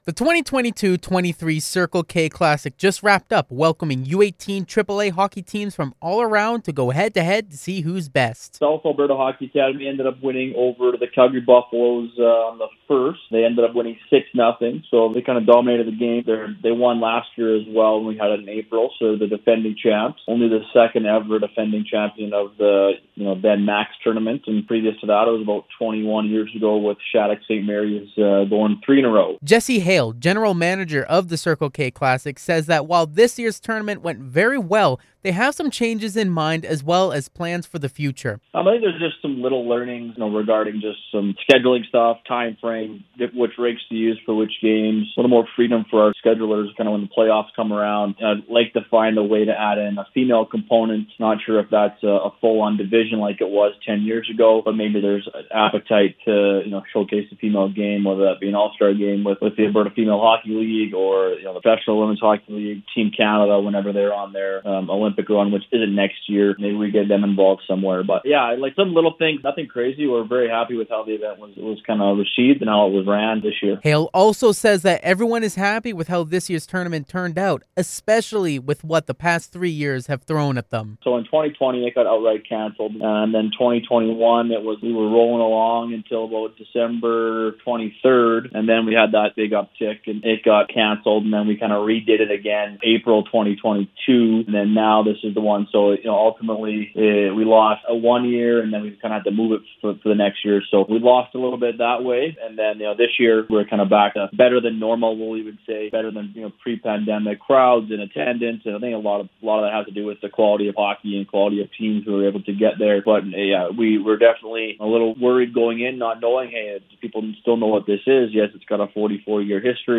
Listen to the full CFWE Interview